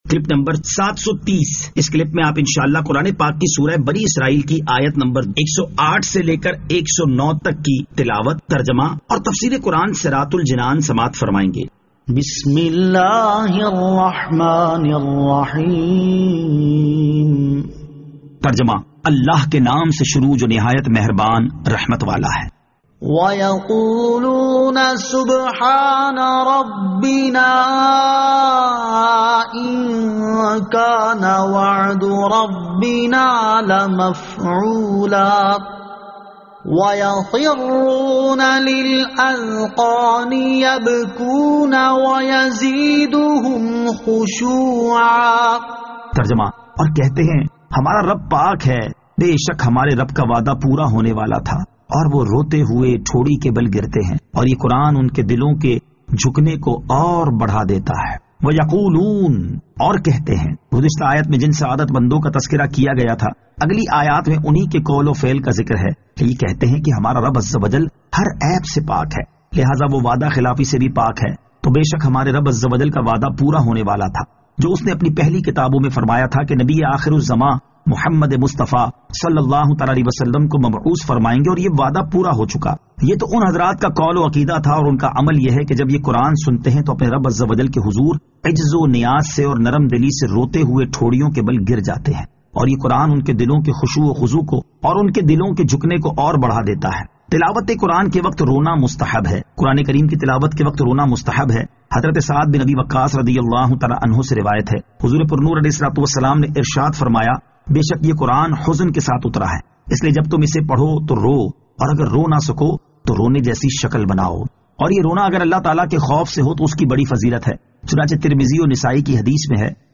Surah Al-Isra Ayat 108 To 109 Tilawat , Tarjama , Tafseer